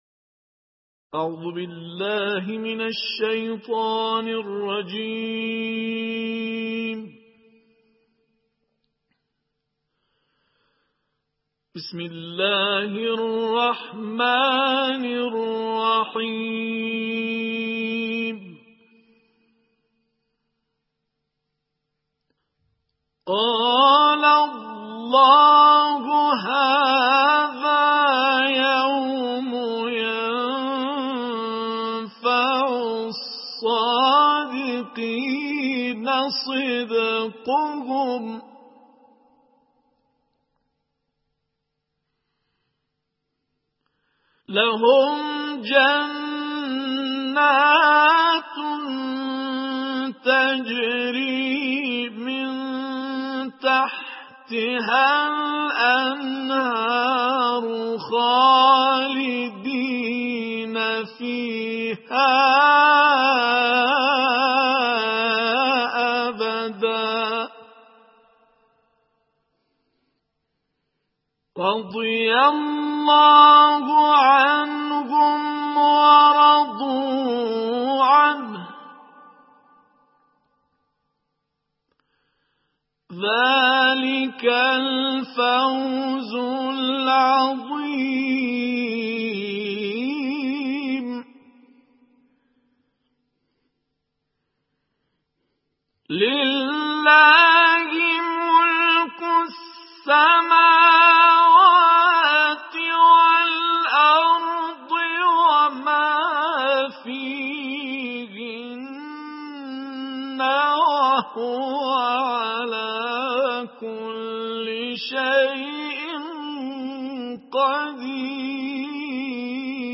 تلاوت آیاتی از سوره مائده